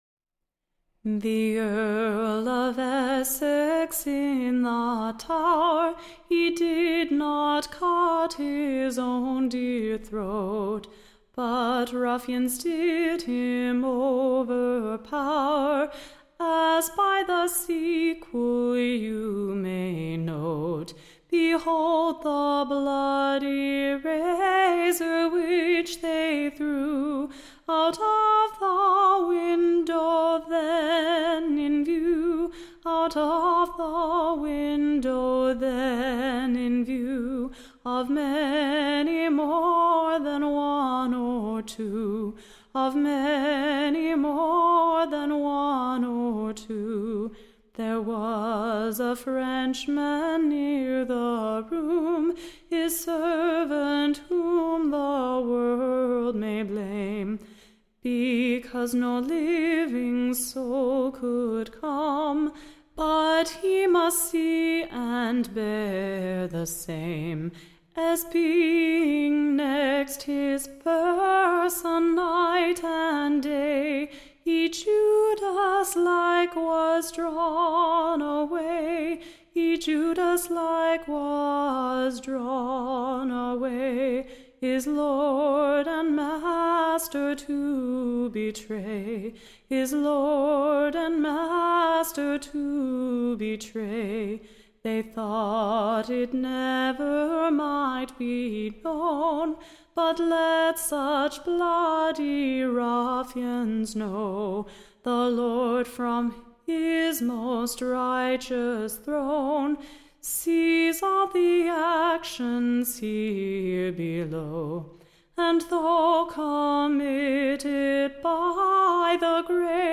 Recording Information Ballad Title ROMES Cruelty: / OR, / The Earl of ESSEX Barbarously Murthered in the Tower.